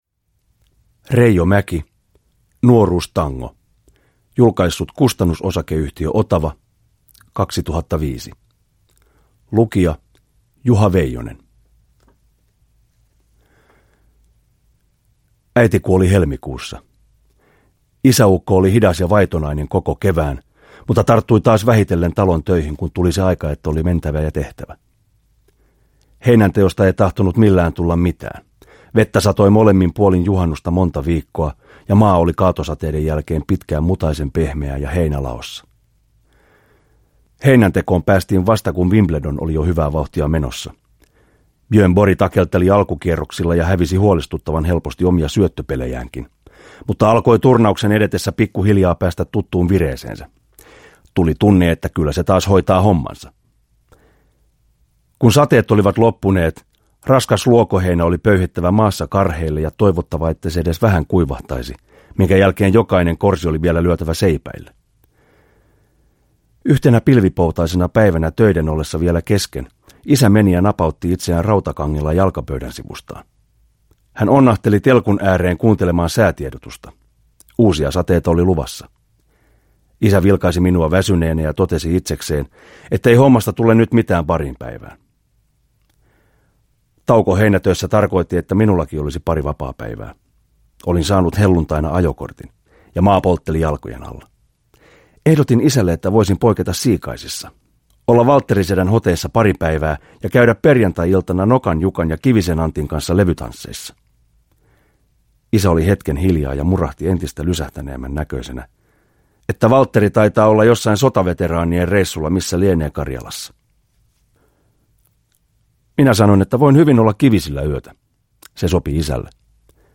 Nuoruustango – Ljudbok – Laddas ner